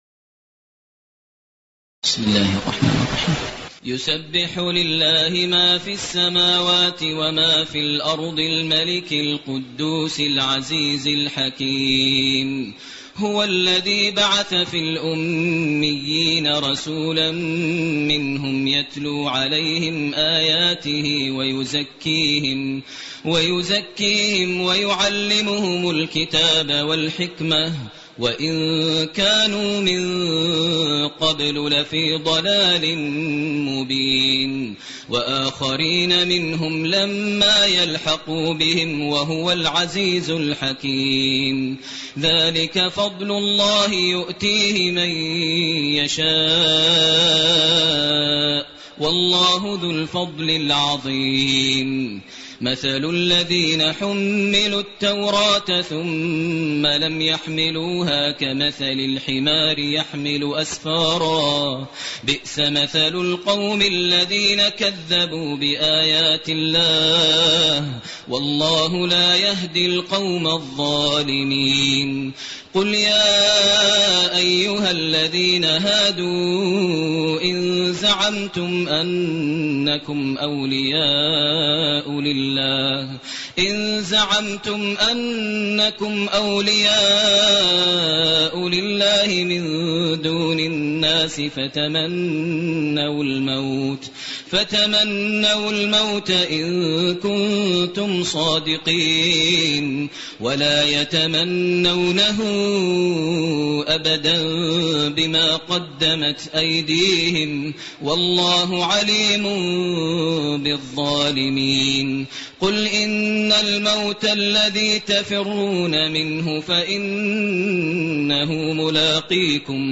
المكان: المسجد النبوي الشيخ: فضيلة الشيخ ماهر المعيقلي فضيلة الشيخ ماهر المعيقلي الجمعة